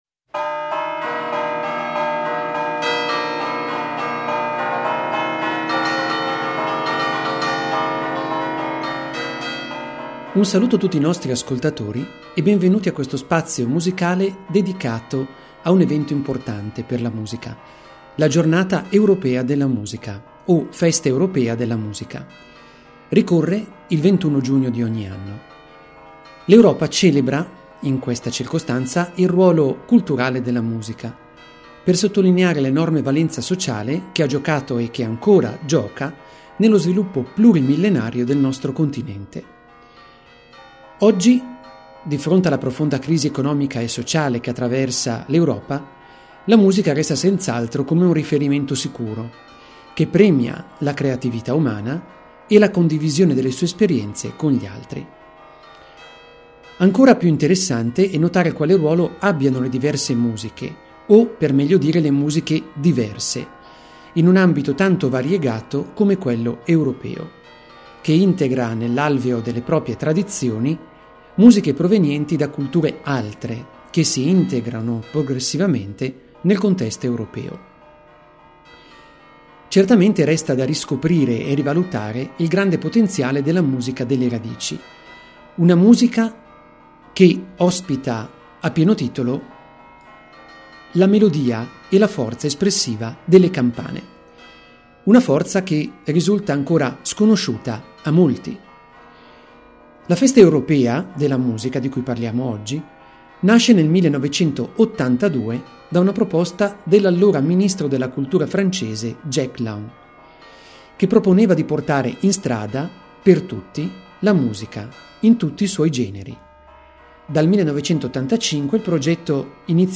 Interviste audio ai nostri giovani musicisti.